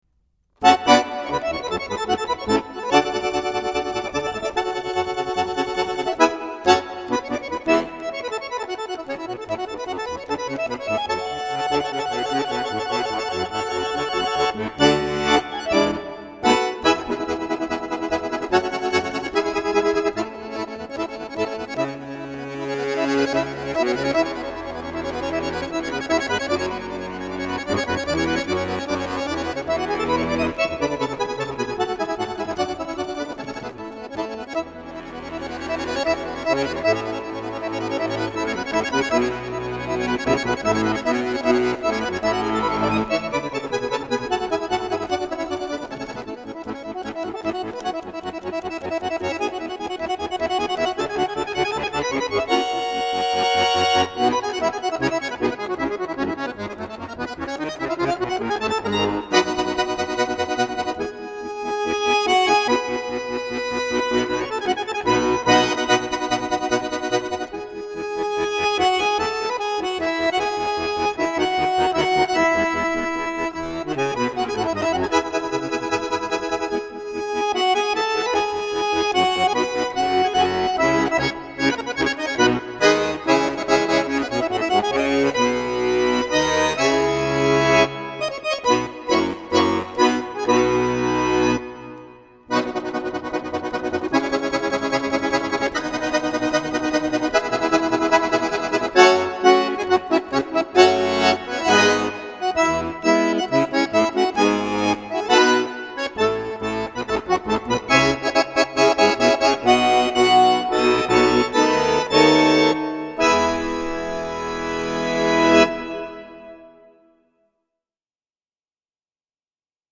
一位极具魔力的乌克兰青年手风琴演奏家， 手风琴发烧天碟，晶体通透，自然无瑕的 手风琴音色醉人心弦。